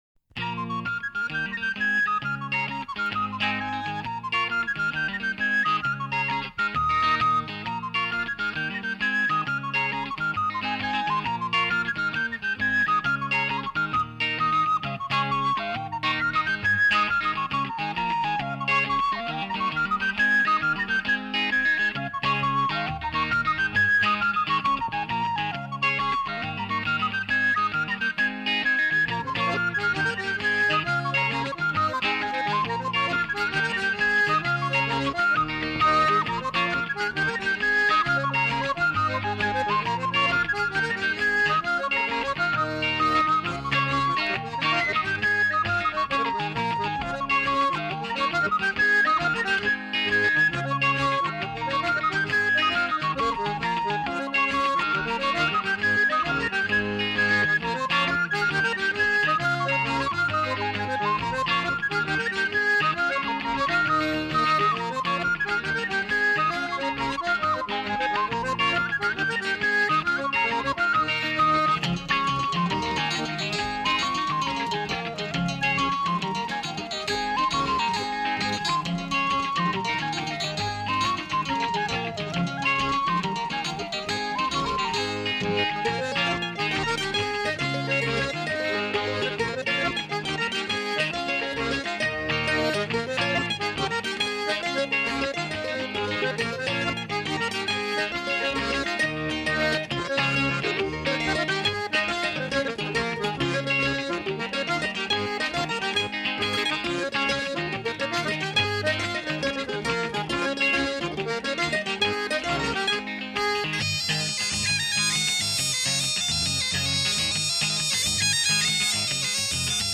An East Anglian Ceilidh Band